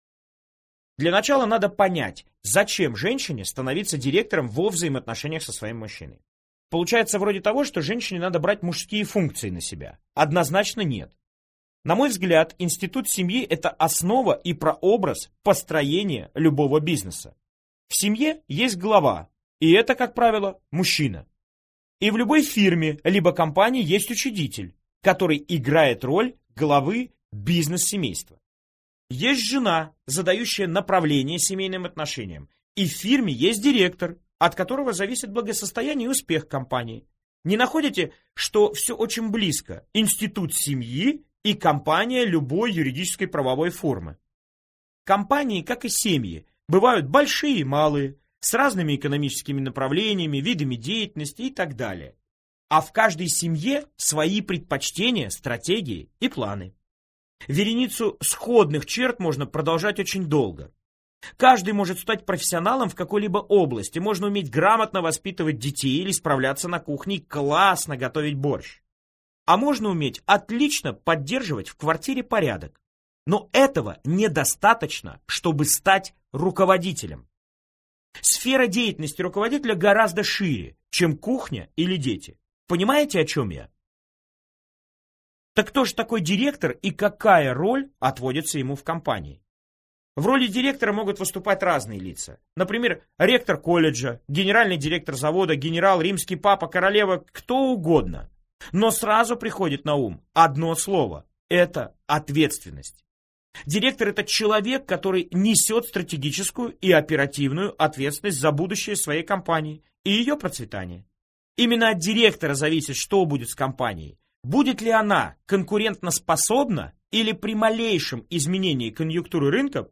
Аудиокнига Жена – директор или грамотное управление семьей | Библиотека аудиокниг